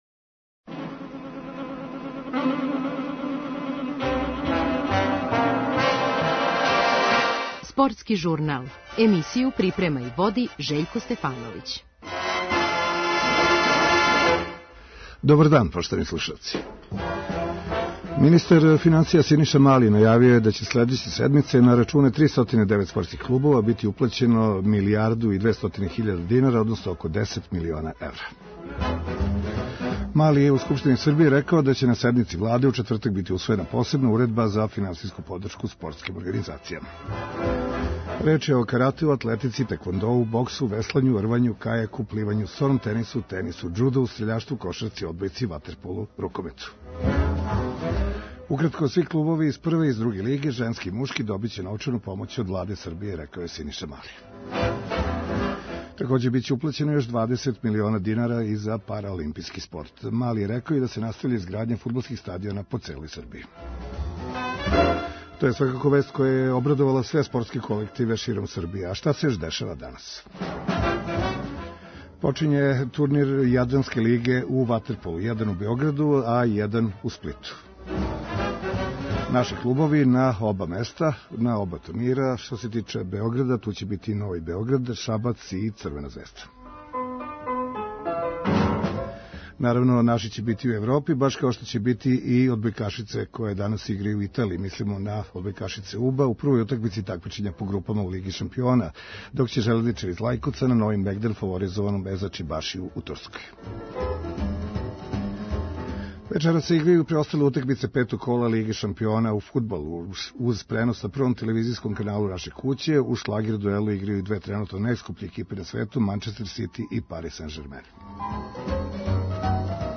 Чућемо, током емисије, неке од изјава наших представника у овом такмичењу.